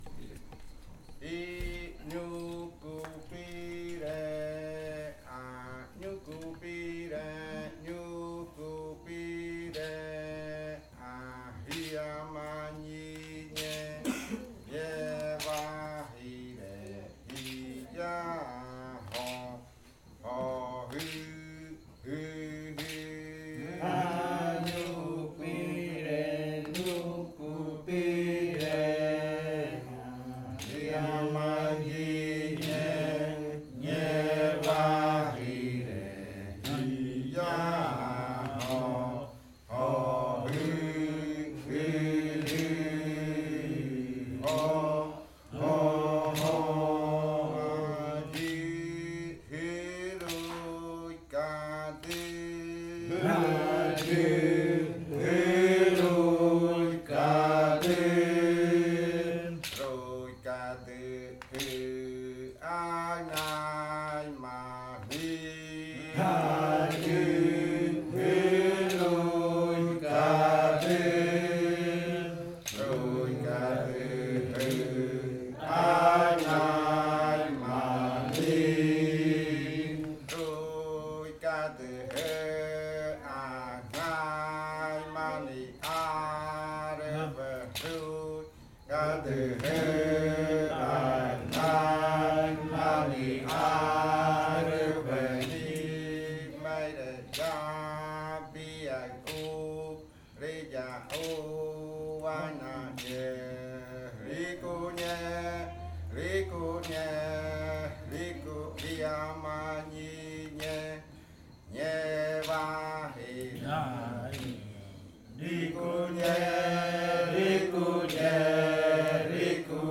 Primer canto de entrada (arrimada) de la variante jaiokɨ
Leticia, Amazonas
con el grupo de cantores sentado en Nokaido.
with the group of singers seated in Nokaido. This song is part of the collection of songs from the yuakɨ murui-muina ritual (fruit ritual) of the Murui people, performed by the Kaɨ Komuiya Uai Dance Group with the support of a solidarity outreach project by UNAL-Amazonia.